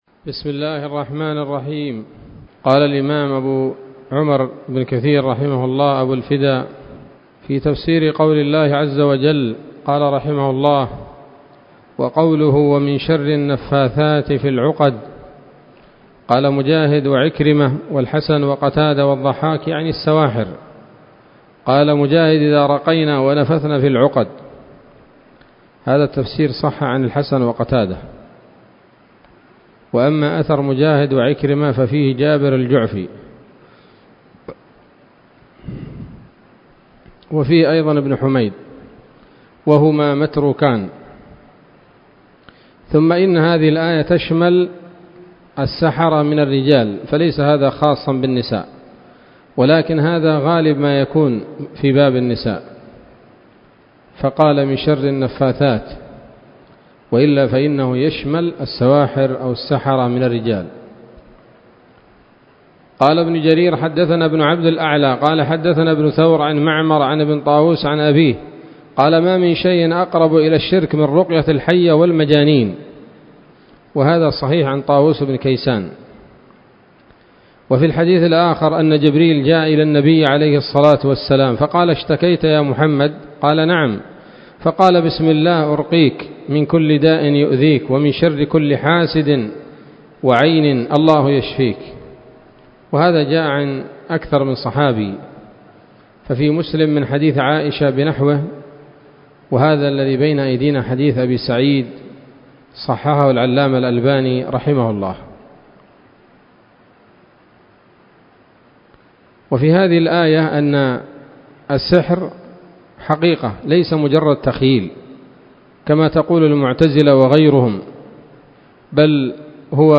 الدرس الرابع وهو الأخير من سورة الفلق من تفسير ابن كثير رحمه الله تعالى